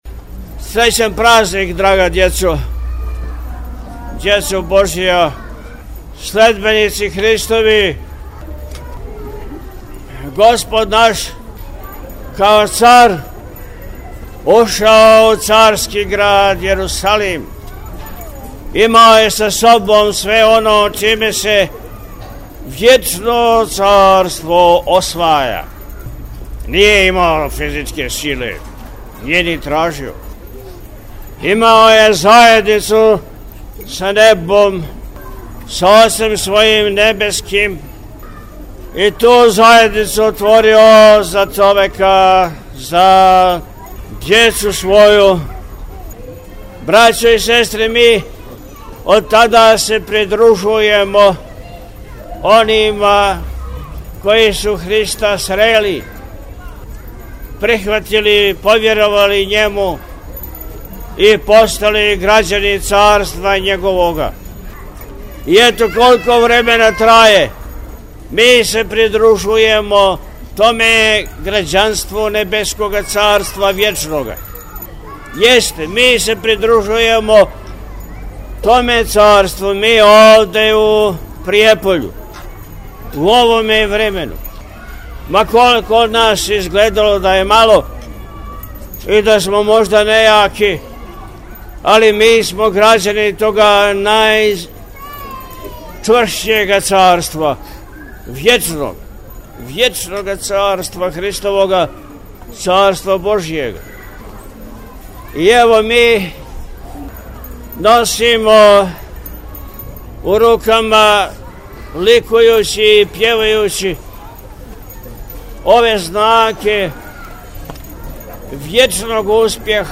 У навечерје празника Уласка Господа Исуса Христа у Јерусалим– Цвети, 4. априла 2026. године, Његово Високопреосвештенство Архиепископ и Митрополит милешевски г. Атанасије служио је у Саборном храму Светог Василија Острошког у Пријепољу свечано Празнично вечерње са Петохлебницом.